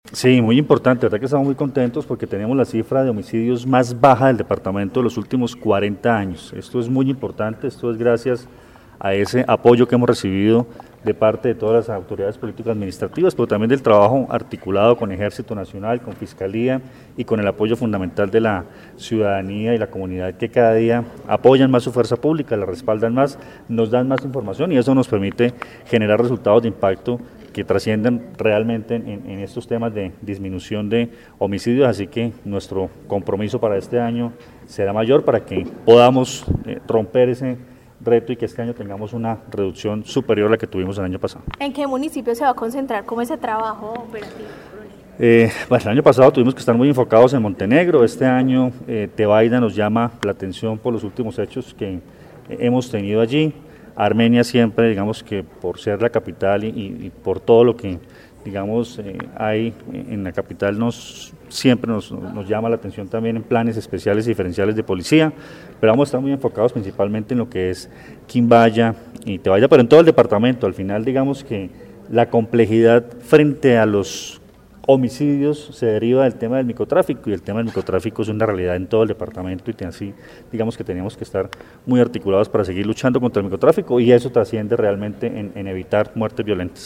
Coronel Luis Fernando Atuesta, comandante de la Policía del Quindío
El balance fue dado a conocer en medio de una rueda de prensa con diferentes autoridades del departamento destacando la articulación para fomentar los buenos resultados en materia de seguridad ciudadana.